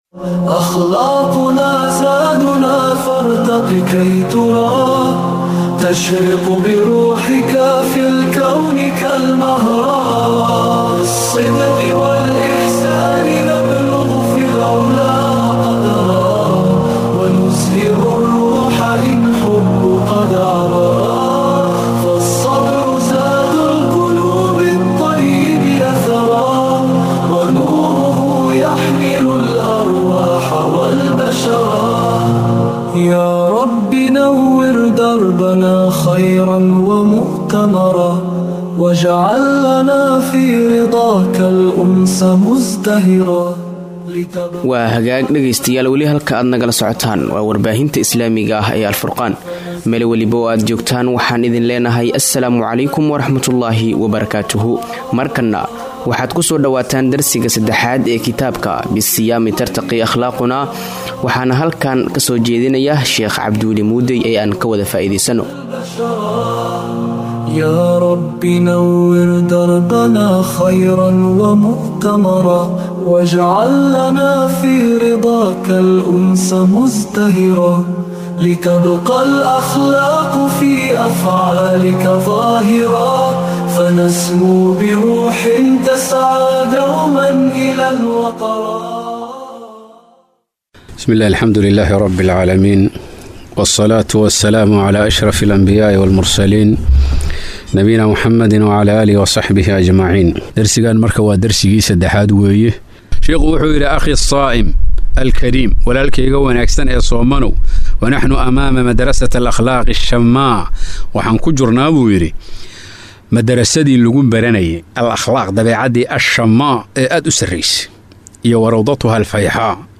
Dersiga 03aad